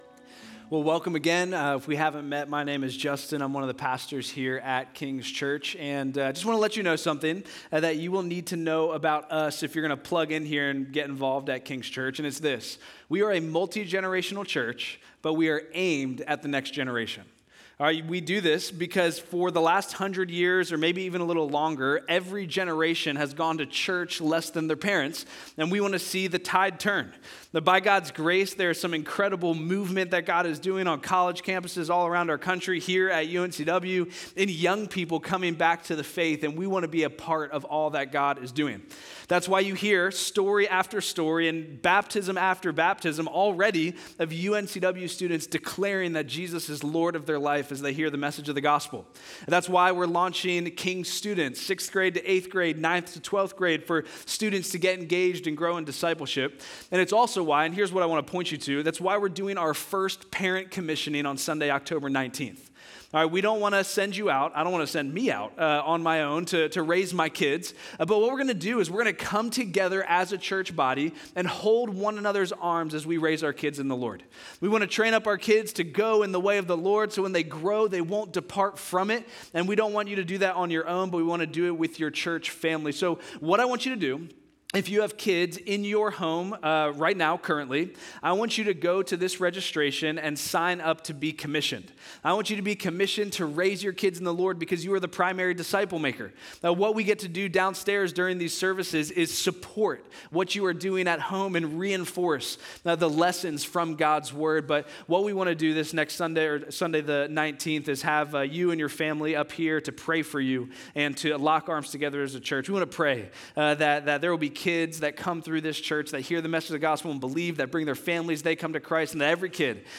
10:6:25 Sermon (Audio).m4a